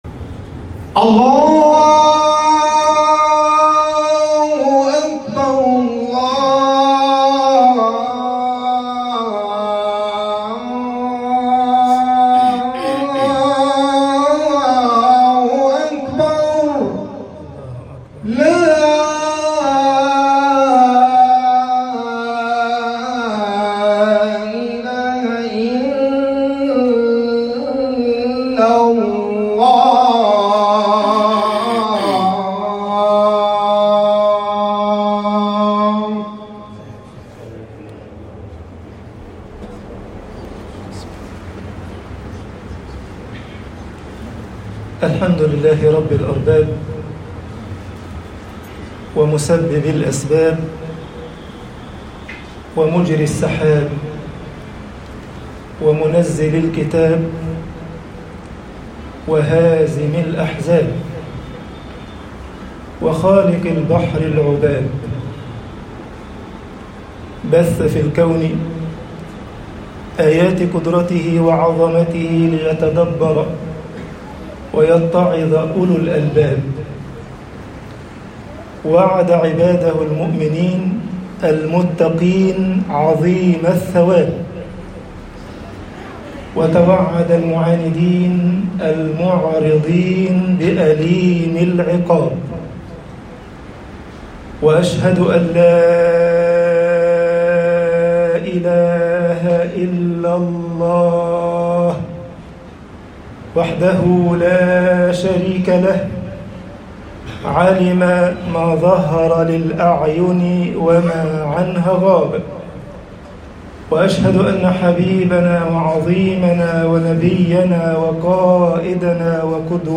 خطب الجمعة - مصر الشِّدَّةُ شَدَّةٌ طباعة البريد الإلكتروني التفاصيل كتب بواسطة